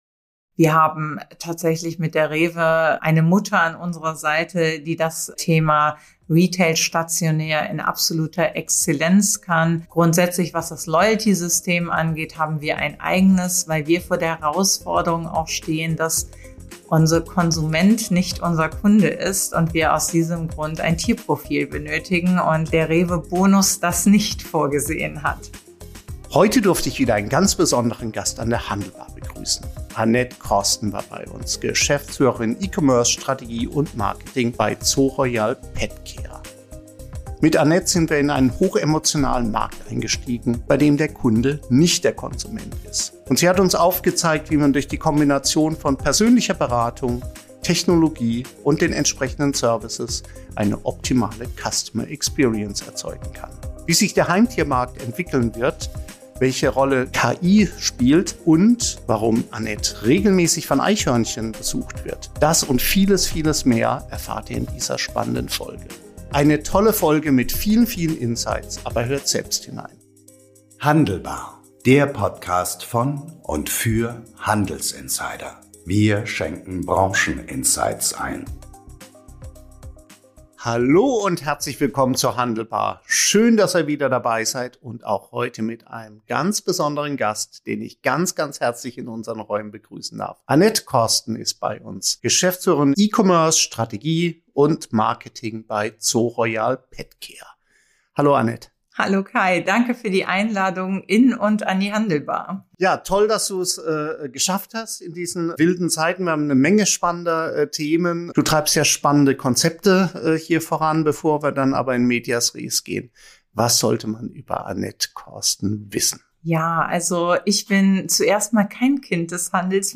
zu Gast und gibt spannende Einblicke in die Transformation eines Heimtierhändlers, der längst mehr ist als „nur online“.